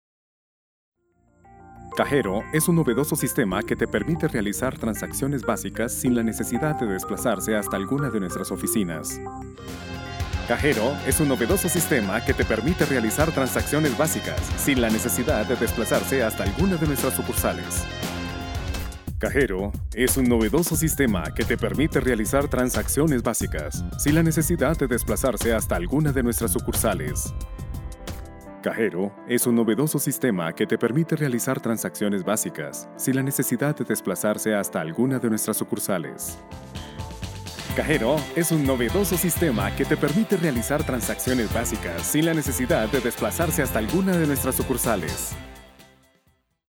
Locutor Colombiano, entregando una voz que interpreta pasión, ternura, seguridad, respaldo.. lo que usted esta buscando.
kolumbianisch
Sprechprobe: Industrie (Muttersprache):